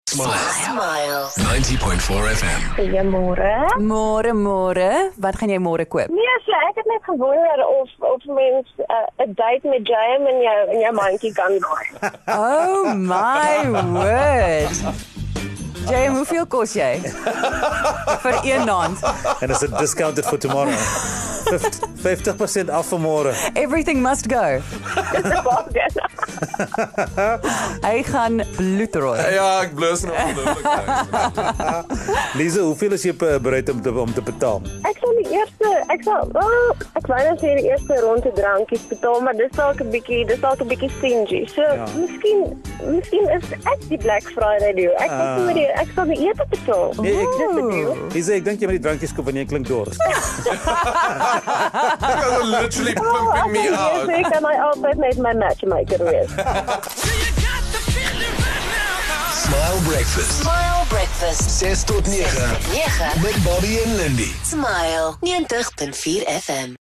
24 Nov This caller wanted a snack in the Smile Breakfast studio